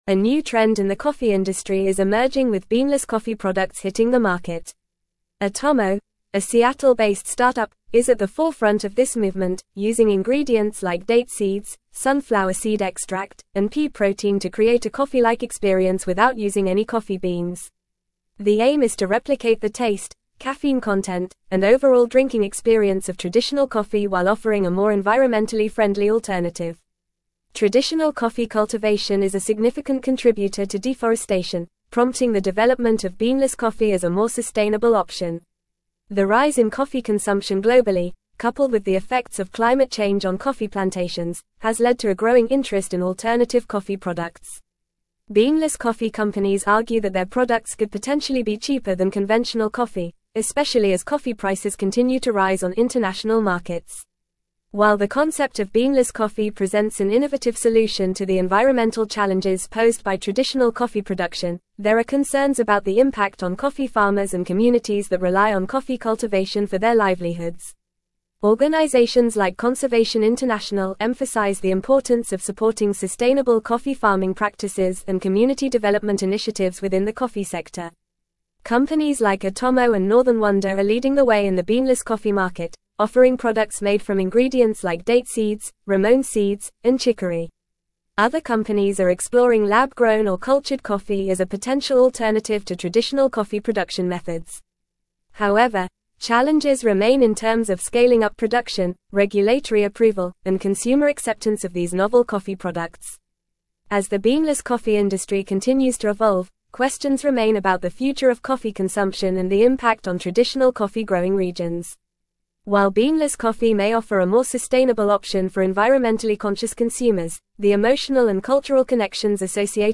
Fast
English-Newsroom-Advanced-FAST-Reading-Emerging-Trend-Beanless-Coffee-Revolutionizing-the-Industry.mp3